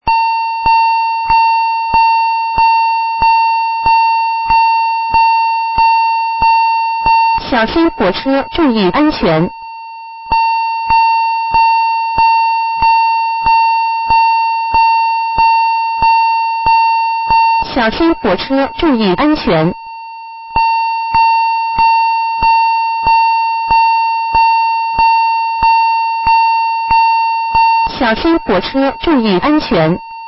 音响发铁路道口 常用警钟声，或警钟声加“小心火车，注意安全”的语音提示声。
警钟加语言